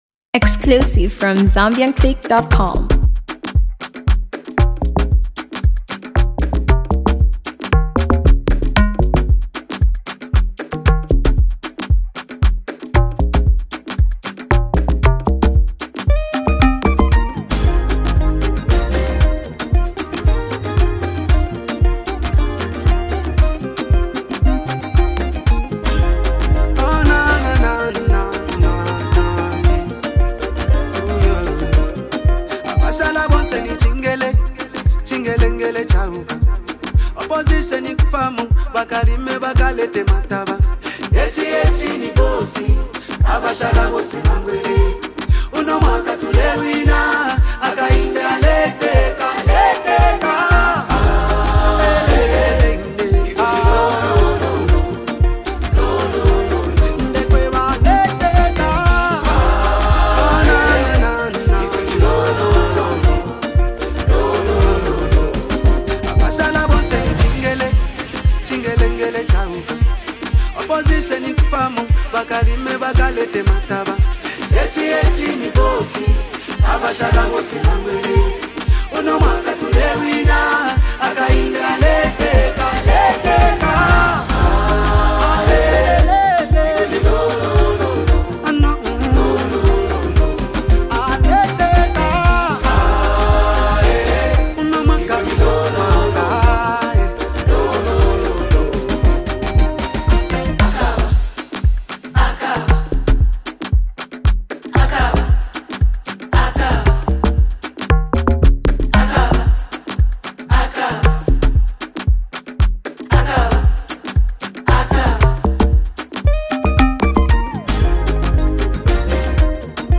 With its catchy hook and signature Afro-pop style